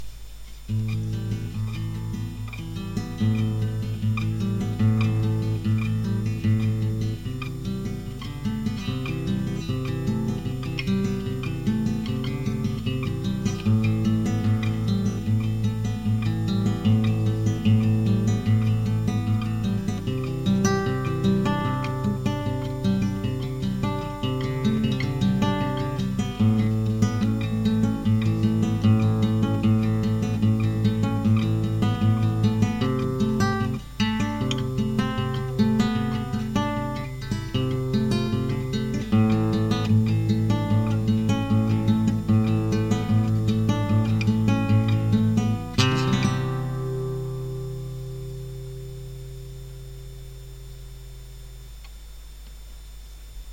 描述：雅马哈C40尼龙吉他录音。
Tag: 古典 尼龙 声学 吉他